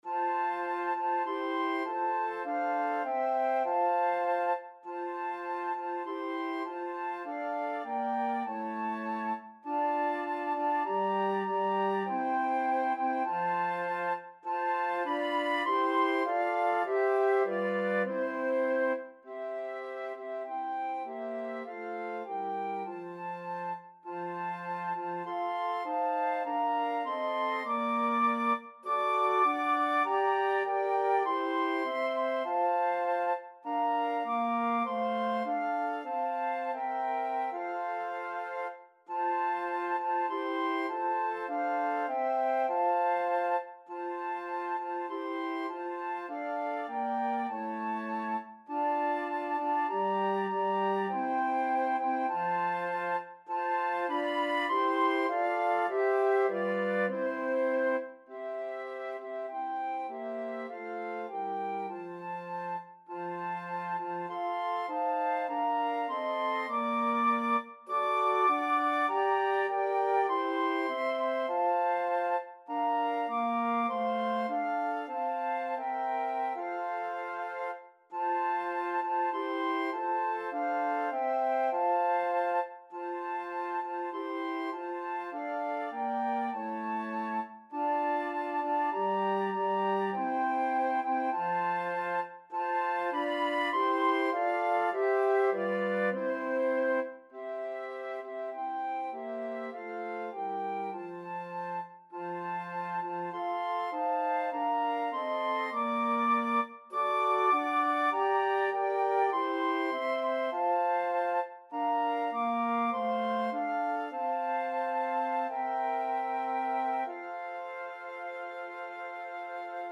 Christian church hymn
flute ensemble